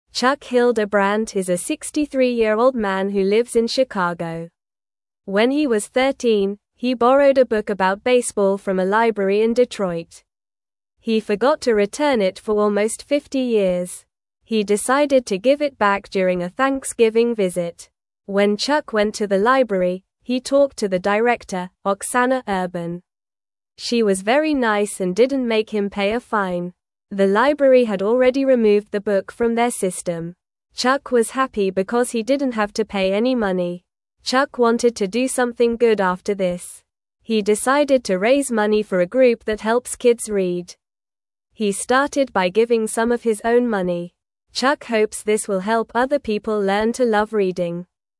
Normal